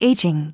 • 音節　age・ing
• 発音記号　/éɪdʒɪŋ/